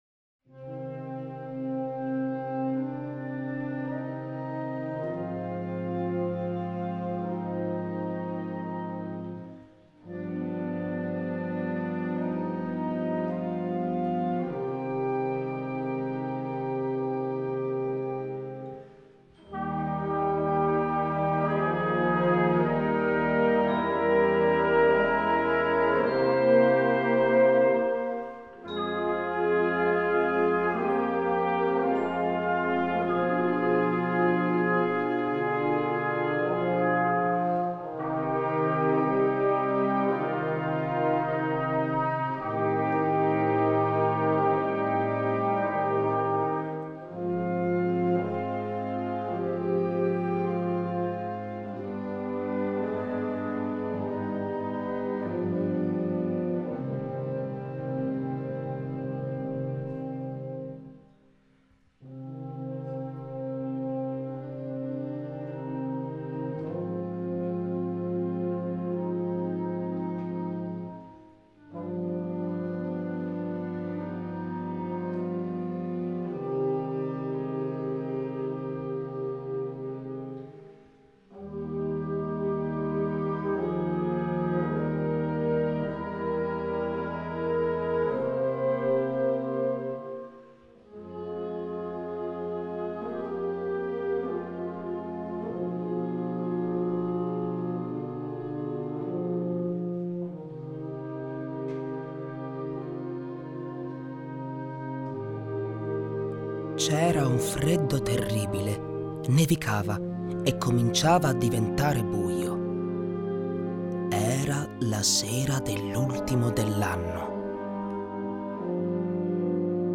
für Erzähler und Blasorchester Dauer